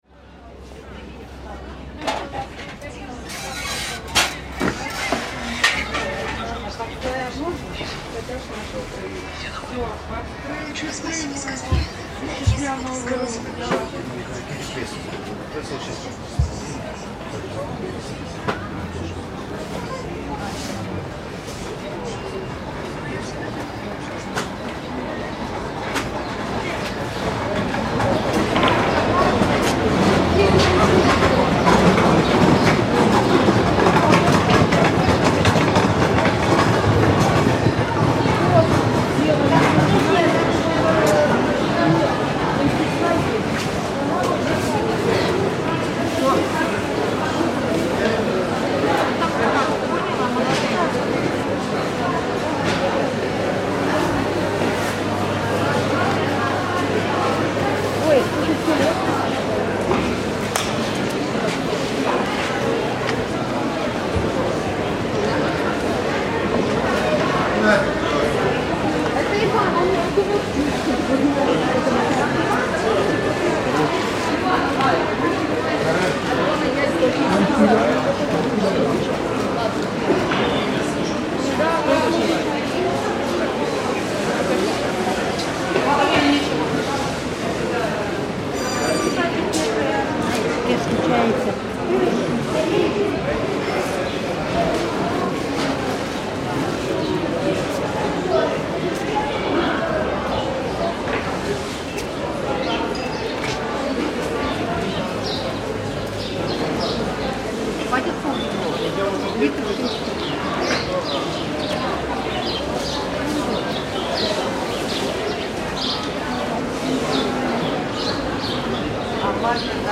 Recording at Pryvoz Market in Odessa was a mix of emotions. The market buzzed with life, as vendors sold goods and people clung to everyday routines despite the ongoing conflict. There was a sense of resilience in the air, but beneath the bustle, a quiet sadness lingered.